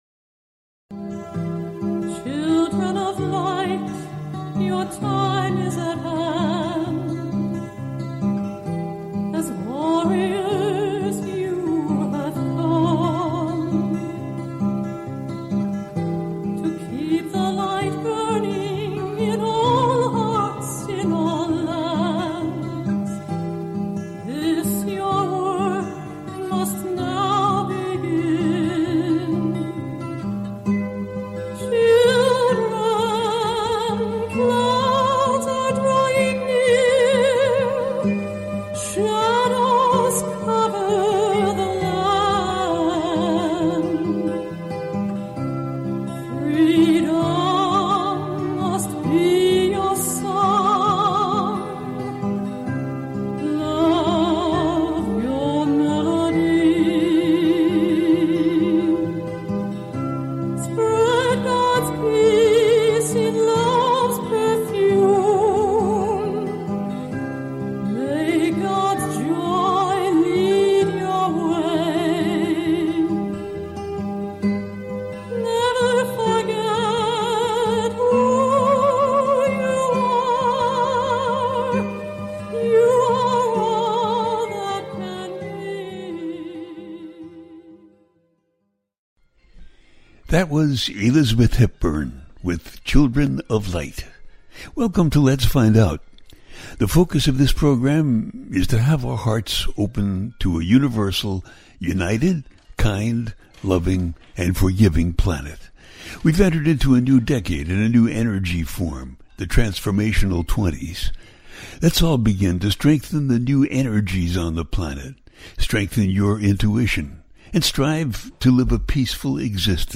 Talk Show
The listener can call in to ask a question on the air.
Each show ends with a guided meditation.